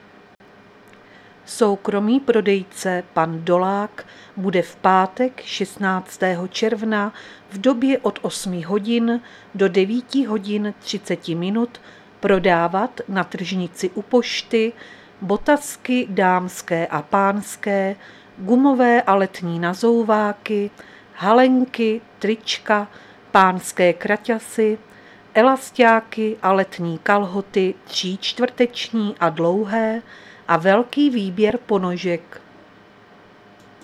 Záznam hlášení místního rozhlasu 15.6.2023